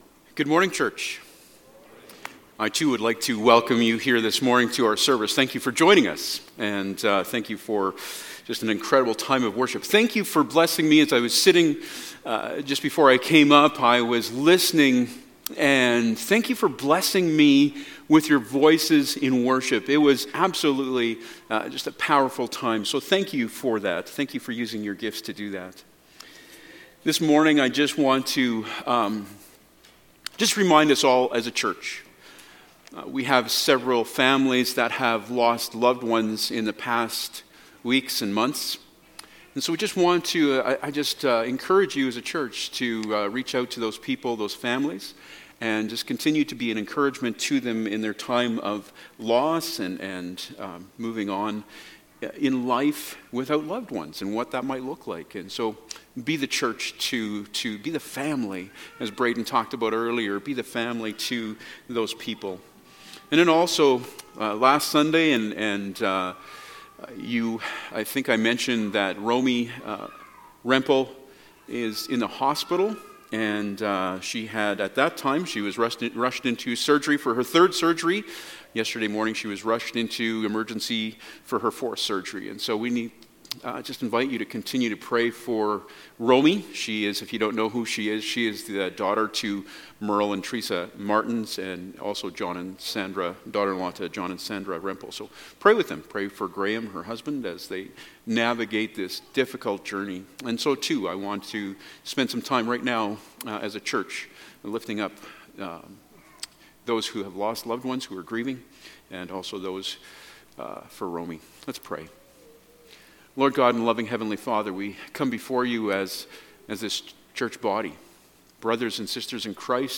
Service Type: Sunday Morning Topics: Stewardship